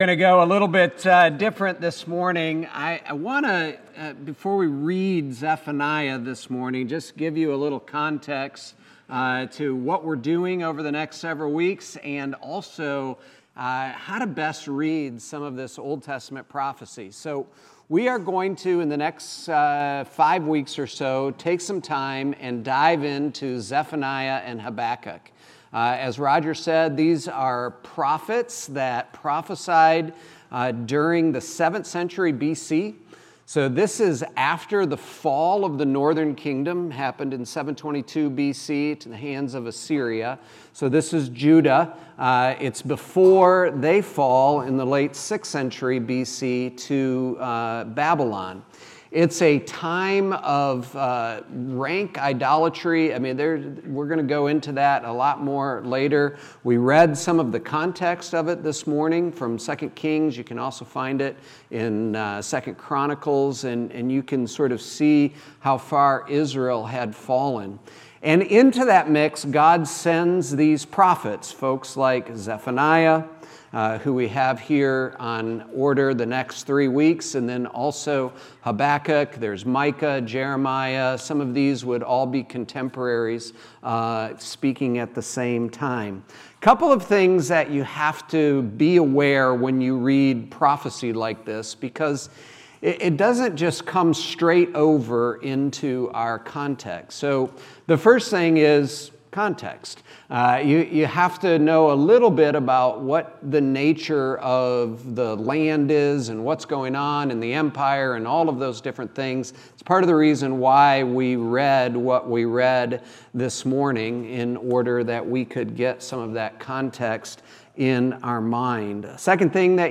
4.27.25 sermon.m4a